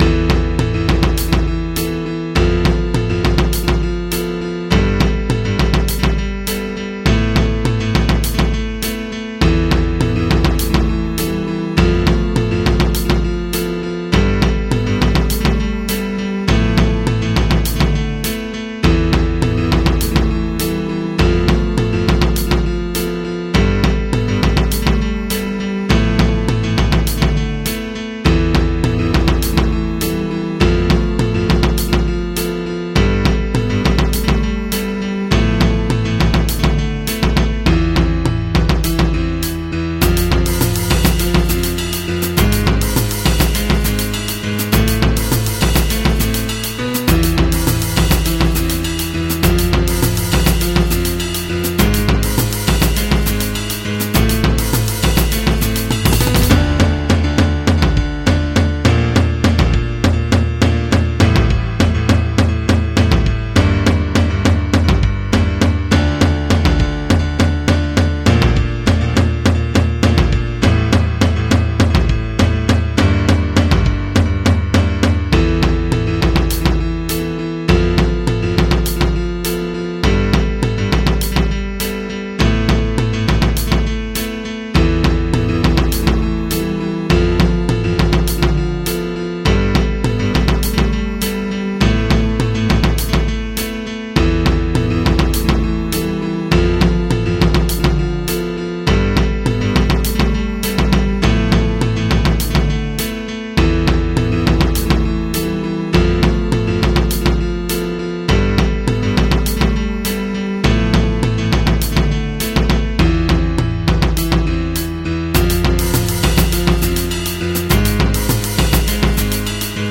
MP3 (Converted)